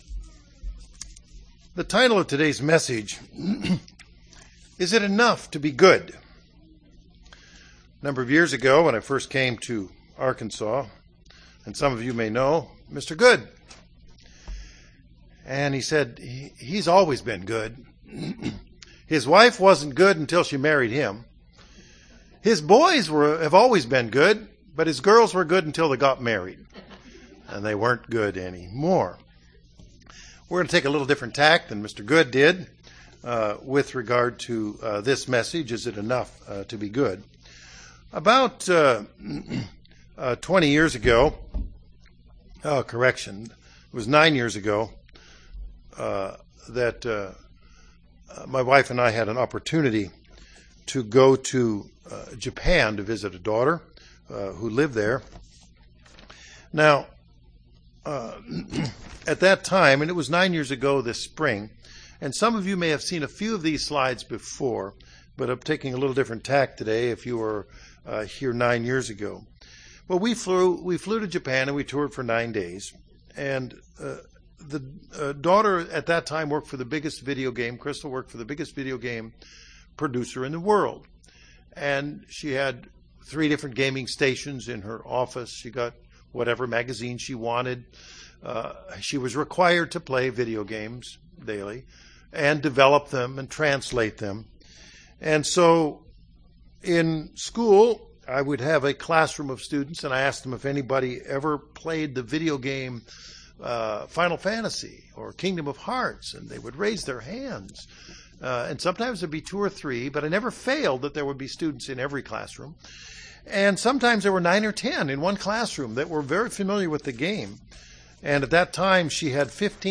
This sermon features some details about Shinto and Buddhist practices with the probing question continually in mind, "Is being good enough?"